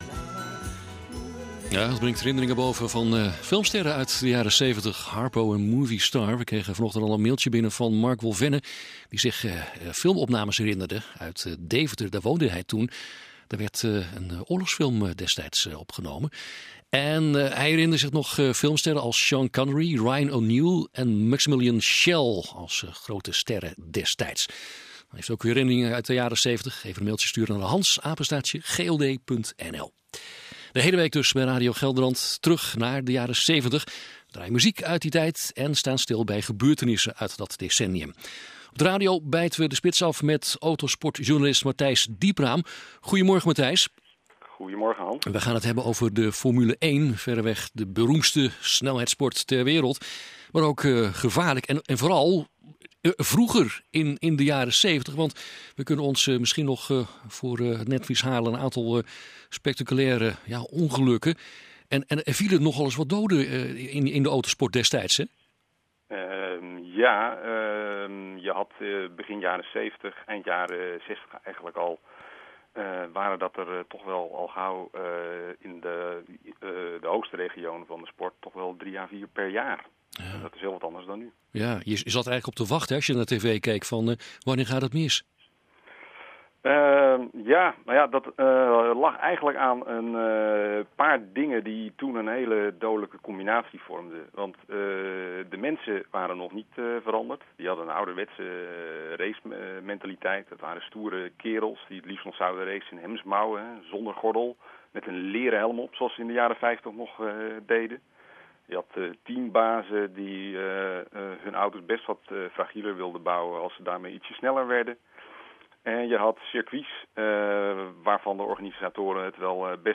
radio-uitzending.
radio-gelderland-seventies-f1.mp3